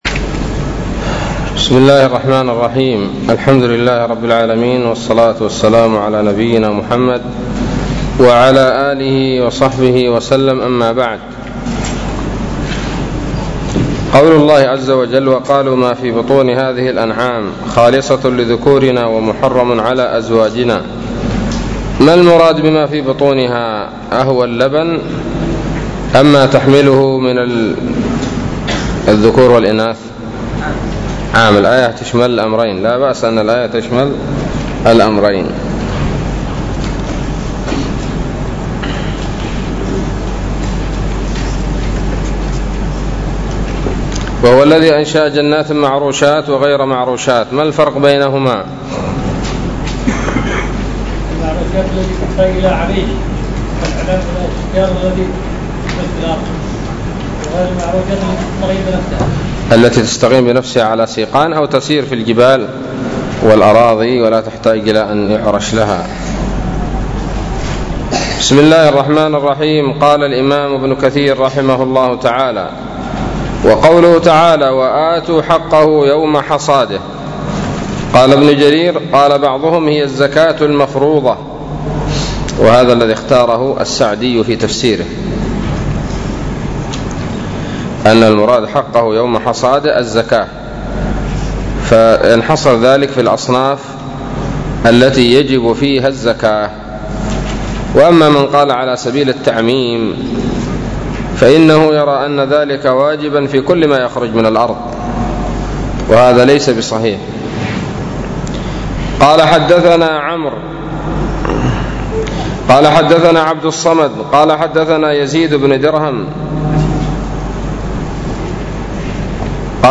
الدرس السابع والخمسون من سورة الأنعام من تفسير ابن كثير رحمه الله تعالى